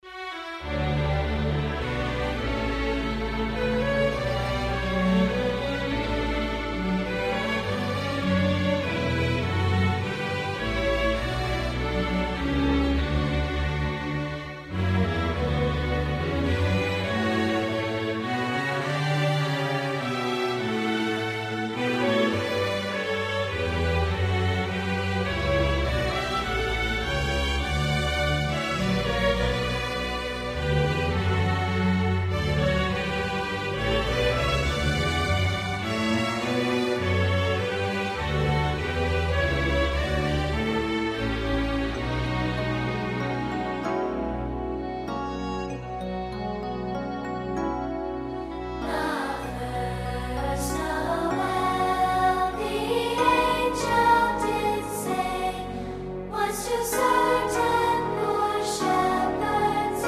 Performers: Bethlehem Children's Chorus
Project: The Bethlehem Children's Chorus